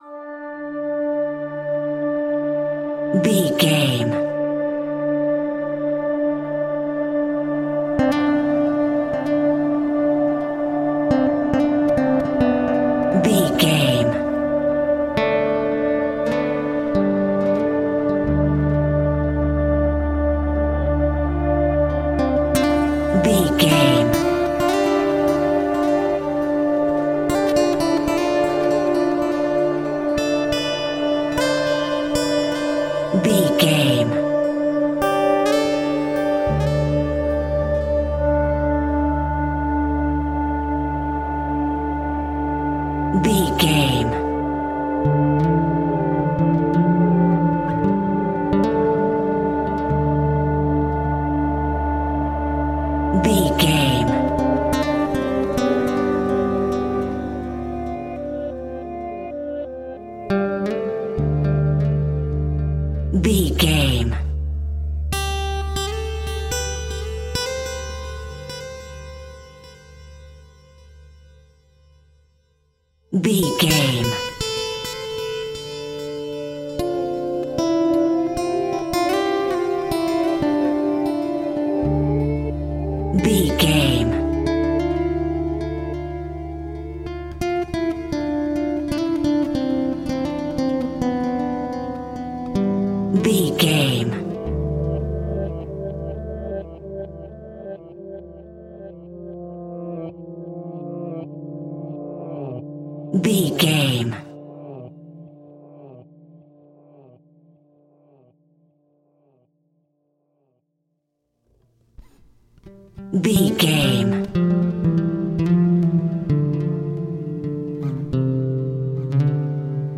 Aeolian/Minor
Slow
spanish ambience
Synth Pads